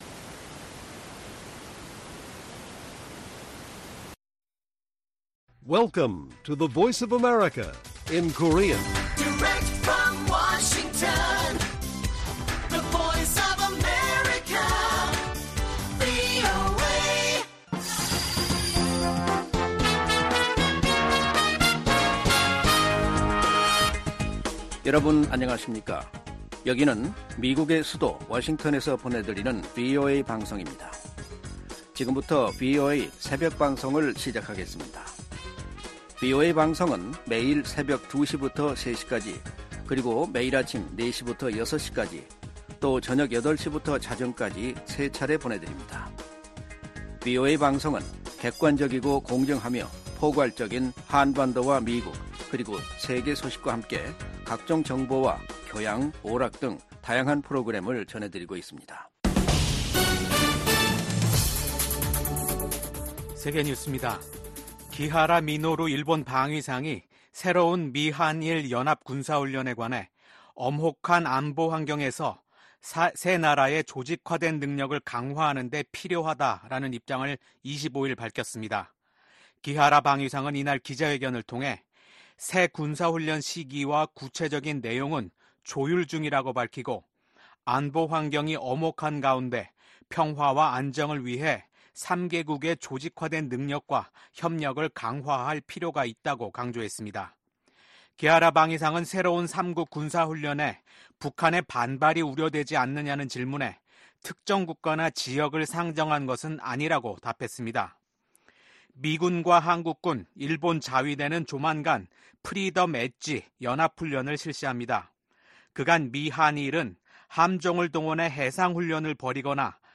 VOA 한국어 '출발 뉴스 쇼', 2024년 6월 26일 방송입니다. 한국에 제공하는 미국의 확장억제 강화는 워싱턴 선언 이행으로 적절한 수준이라고 커트 캠벨 미 국무부 부장관이 말했습니다. 북한이 한국에 또 다시 '오물 풍선'을 살포하고 새로운 방식의 군사 도발 가능성도 내비쳤습니다.